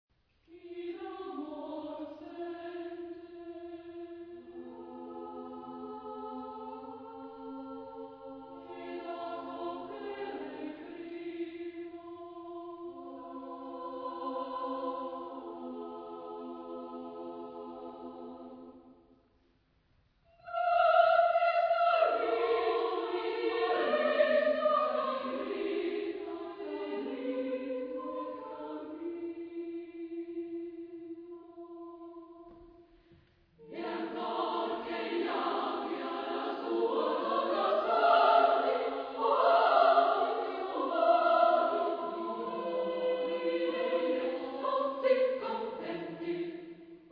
Genre-Style-Forme : Profane ; Madrigal